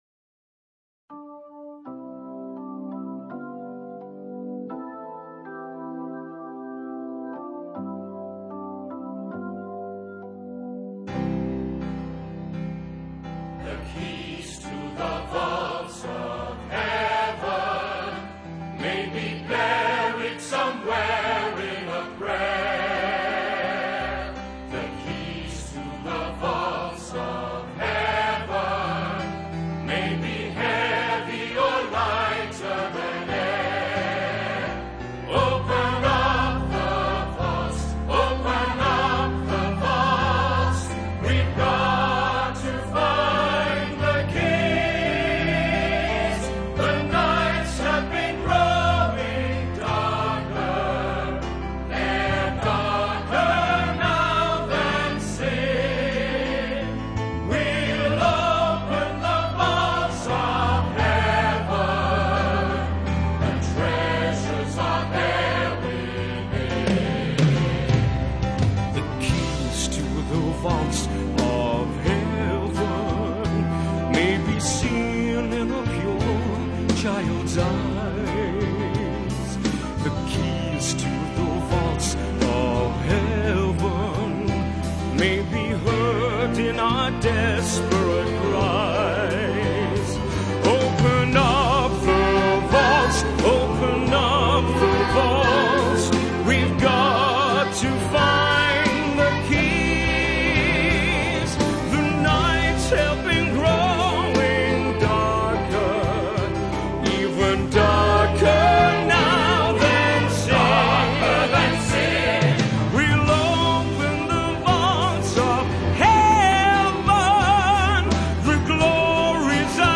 這是片頭曲。